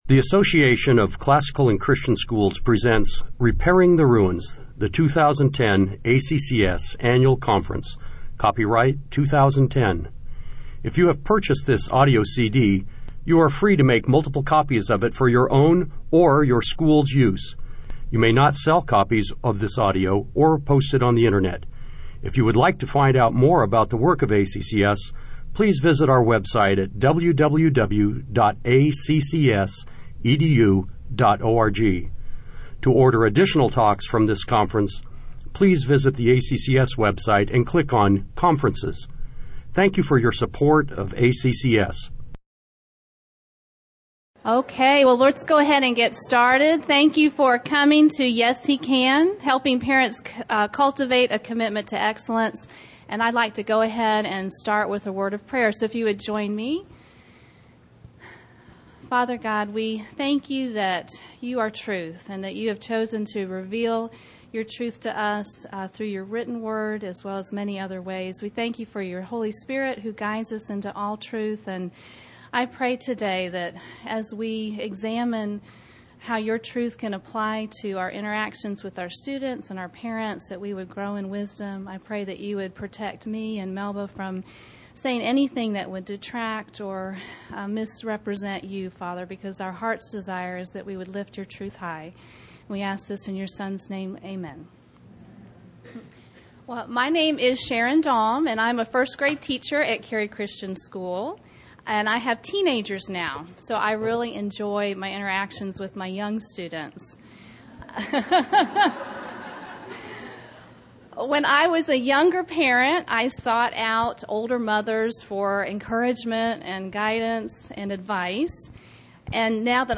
2010 Workshop Talk | 1:06:48 | All Grade Levels, Leadership & Strategic, General Classroom
The Association of Classical & Christian Schools presents Repairing the Ruins, the ACCS annual conference, copyright ACCS.